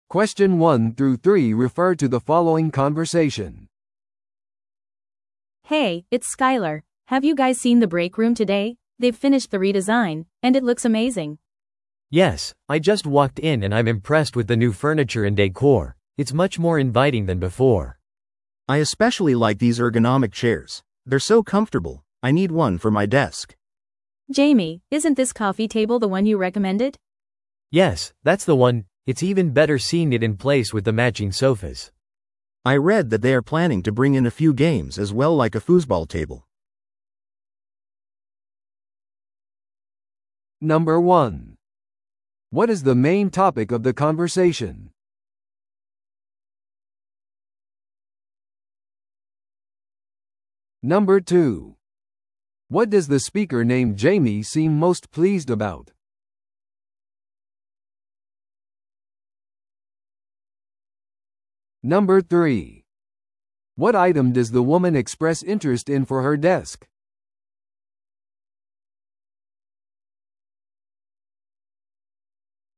No.1. What is the main topic of the conversation?